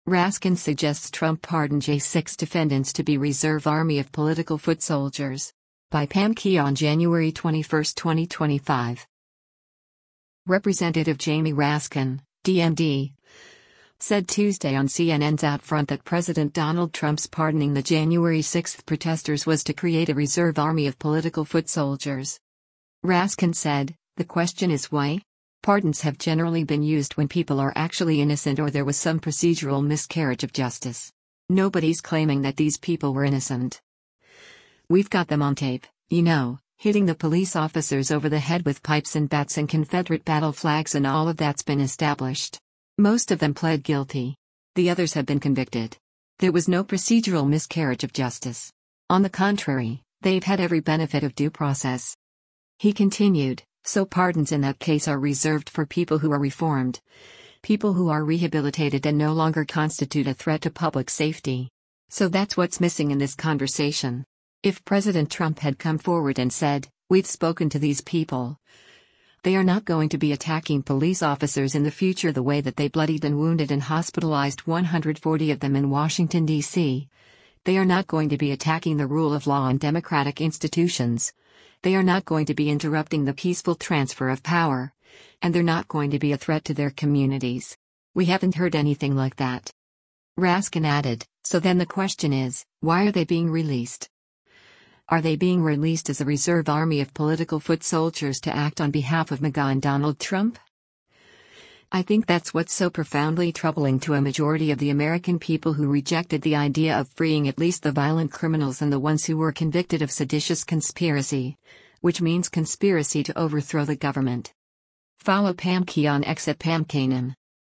Representative Jamie Raskin (D-MD) said Tuesday on CNN’s “OutFront” that President Donald Trump’s pardoning the January 6 protesters was to create a “reserve army of political foot soldiers.”